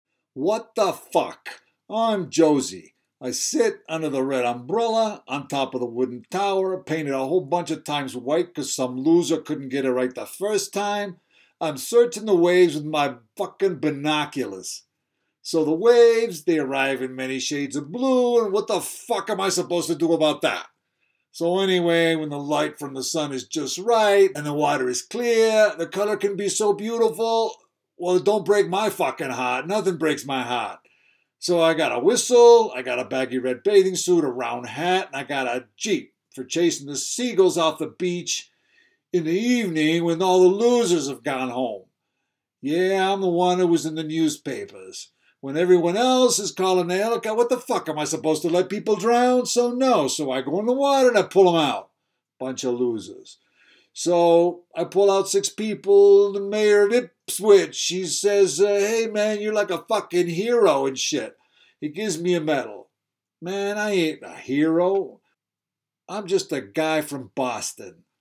prologue_boston.m4a